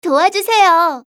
archer_f_voc_sticker_10_b.mp3